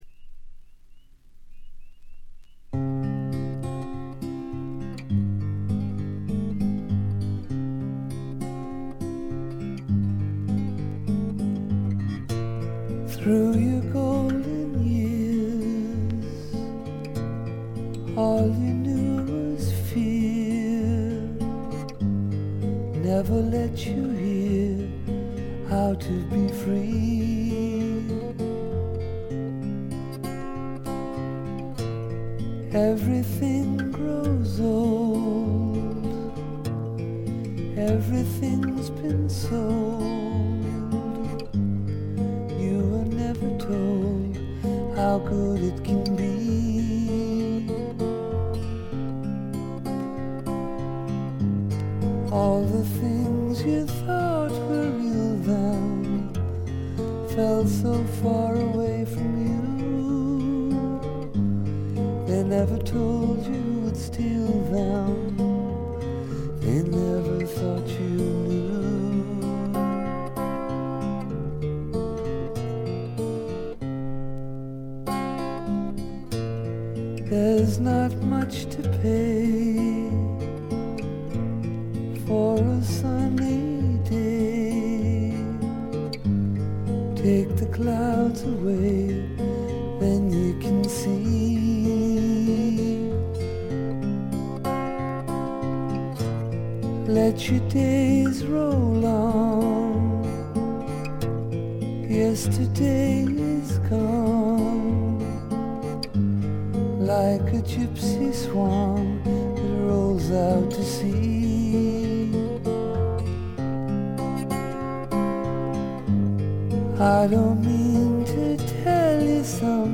これ以外はわずかなノイズ感のみで良好に鑑賞できると思います。
試聴曲は現品からの取り込み音源です。